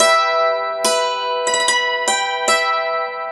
Dulcimer02_144_G.wav